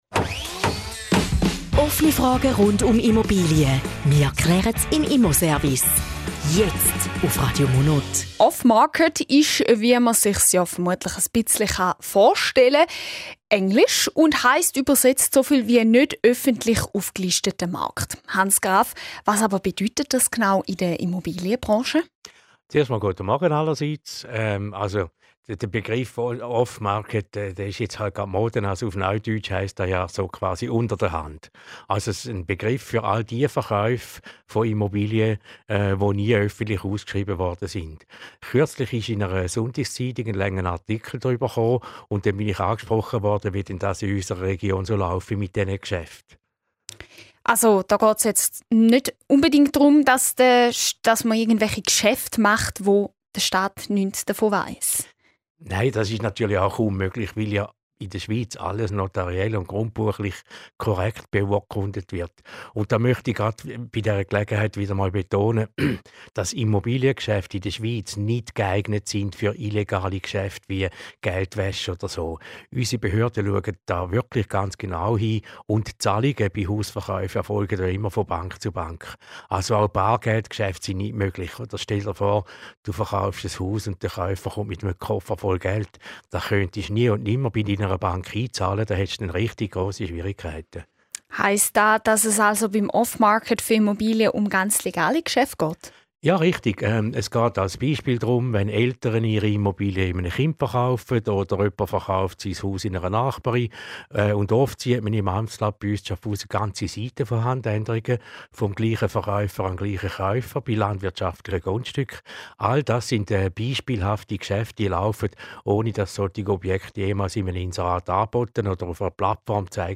Zusammenfassung des Interviews zum Thema "Off-Market":